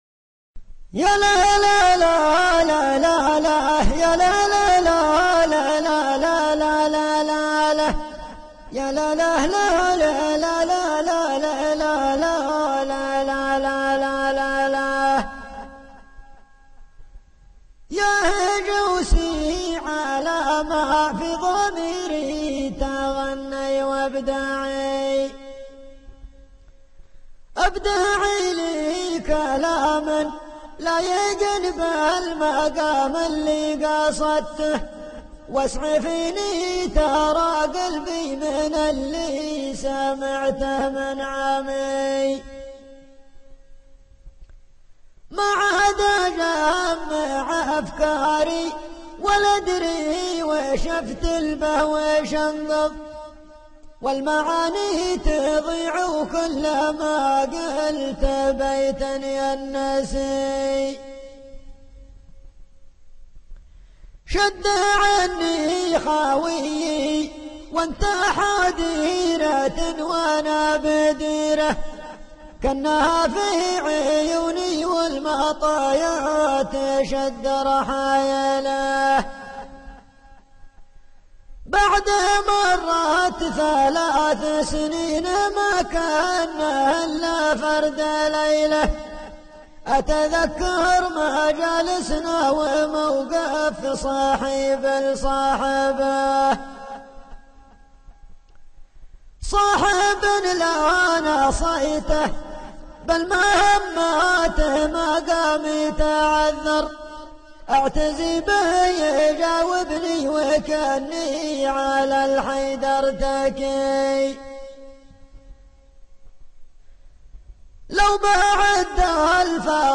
طرق جنوبي